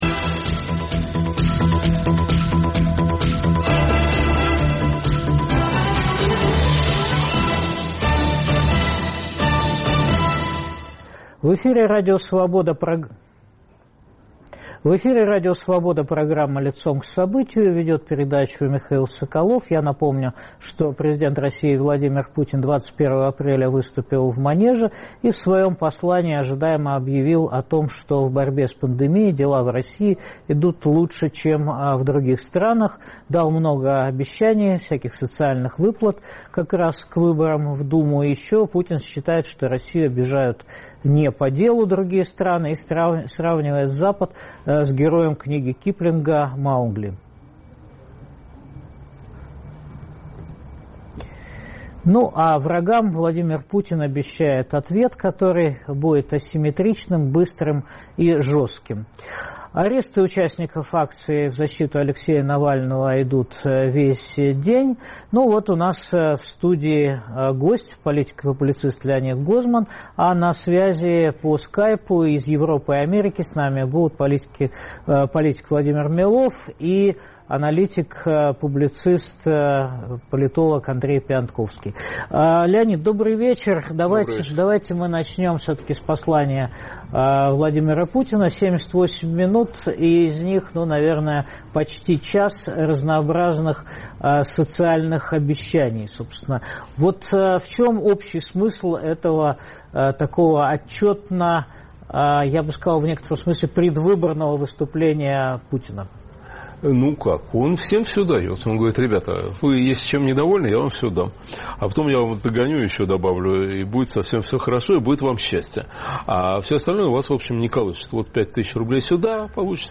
Послание прозвучало на фоне начавшихся в России 21 апреля акций протеста в защиту Алексея Навального. Положение в стране и мире обсуждают политик и психолог Леонид Гозман, политик и экономист Владимир Милов, политолог Андрей Пионтковский.